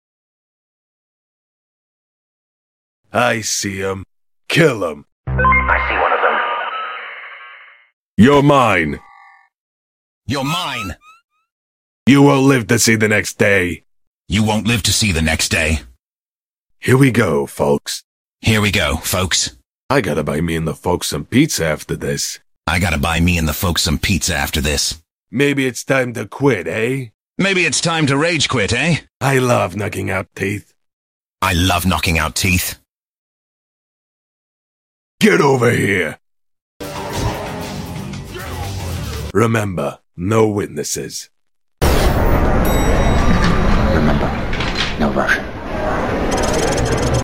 Forsaken new Mafioso, voice line